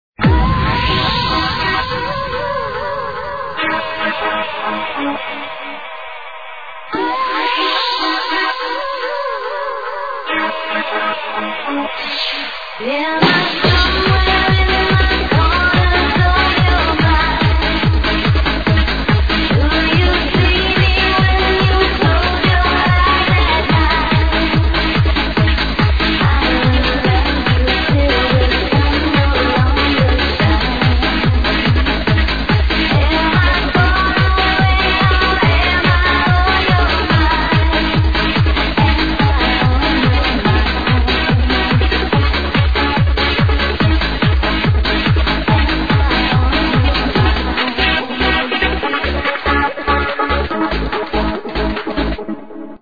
Dunno Unknown Vocal Trance - Help!!